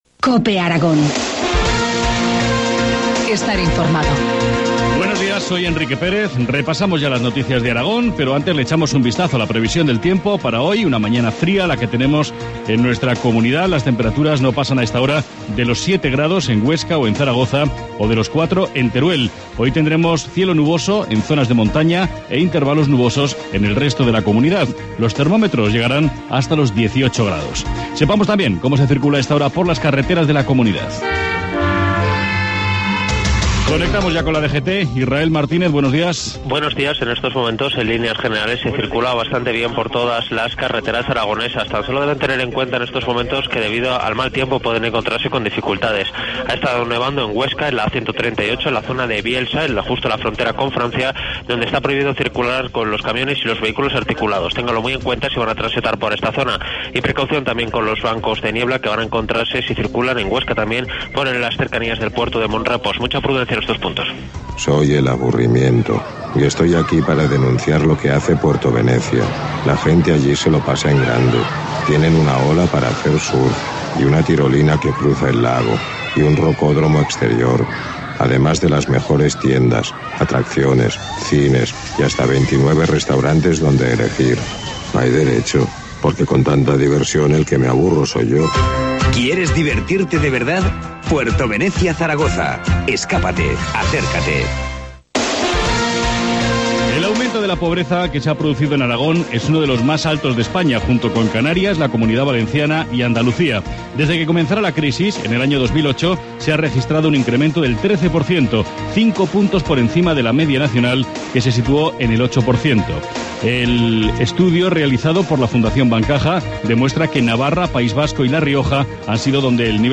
Informativo matinal, jueves 16 de mayo, 7.25 horas